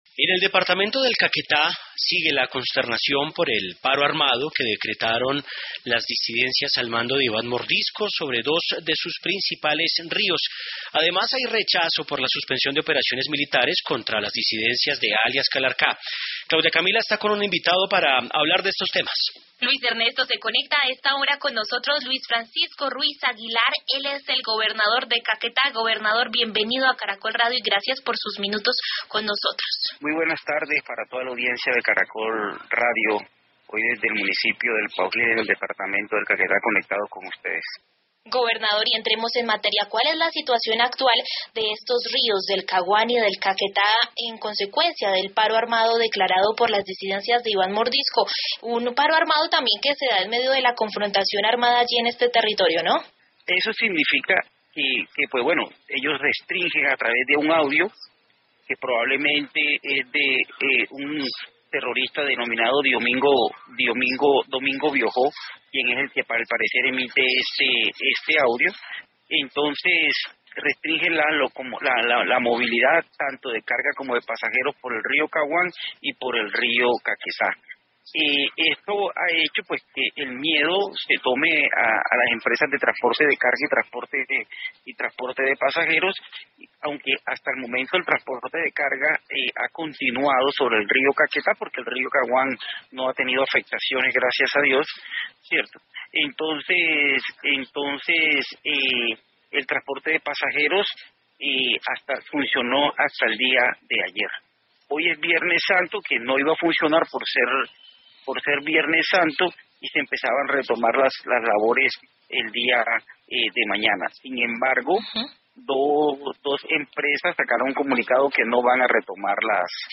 En diálogo con Caracol Radio el gobernador del Caquetá, Luis Francisco Ruiz, manifestó su rechazo frente a la suspensión de operaciones militares contra el Estado Mayor de los Bloques Magdalena Medio ‘Comandante Gentil Duarte’, ‘Comandante Jorge Suárez Briceño’ y el Frente ‘Raúl Reyes Farc-EP’, que adelantan diálogos de paz con el gobierno nacional.